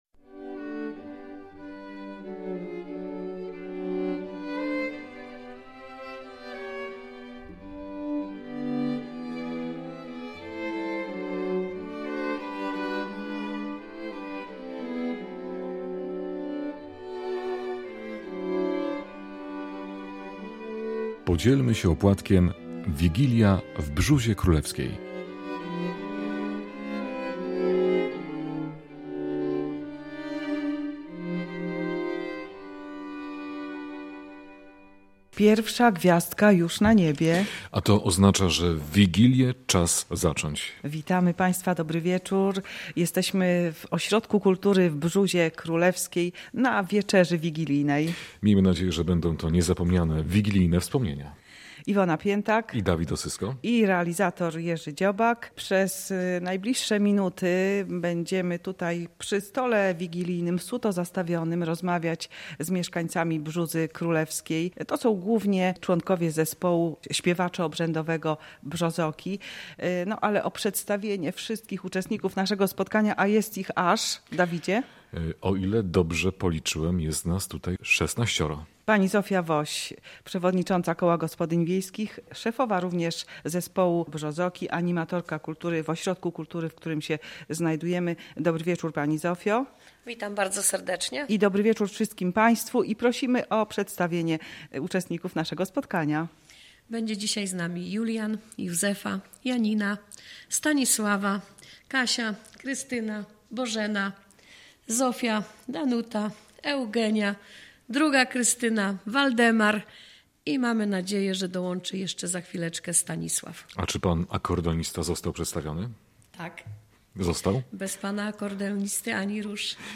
W Wigilię odwiedziliśmy mieszkańców Brzózy Królewskiej (powiat leżajski), którzy opowiedzieli nam o lokalnych zwyczajach i tradycjach związanych z tym dniem, zaśpiewali także piękne polskie kolędy.